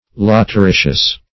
Search Result for " lateritious" : The Collaborative International Dictionary of English v.0.48: Lateritious \Lat"er*i"tious\, a. [L. lateritius, fr. later a brick.]
lateritious.mp3